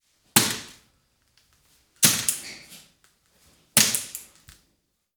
Varios lanzamientos de dardos